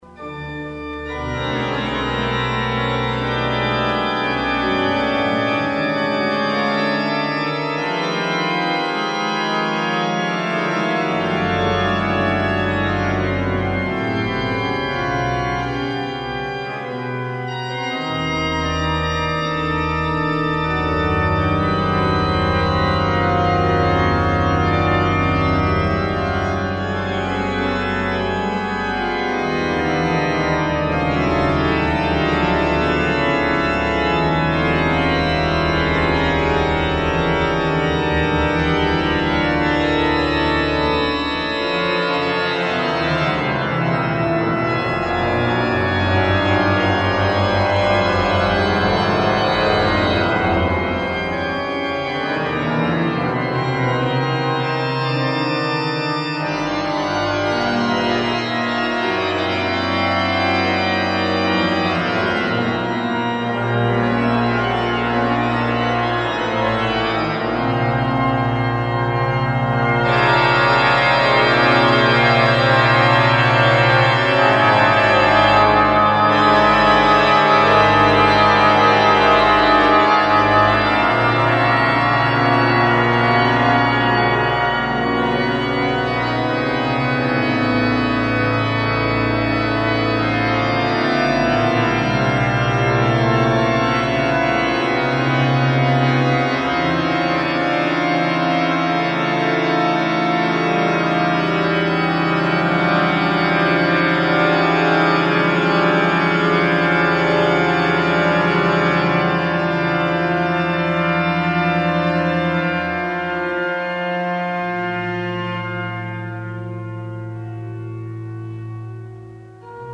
Three meditations on Jeremiah for organ solo, 15'